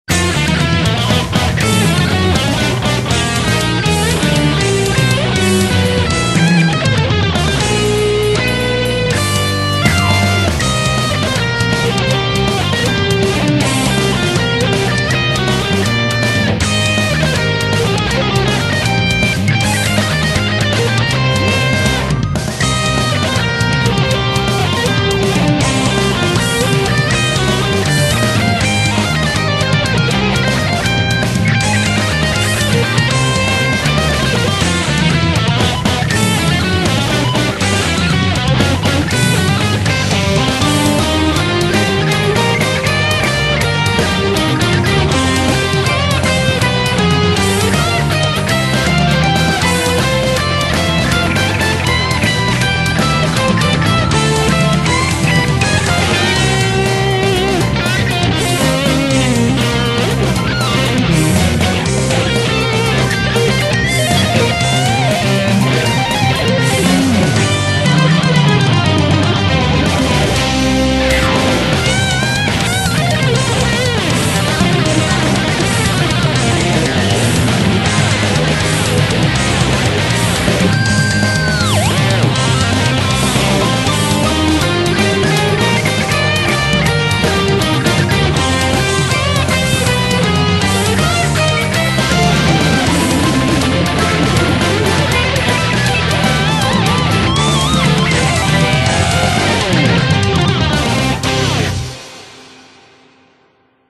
BPM160
Comments[FUSION ROCK]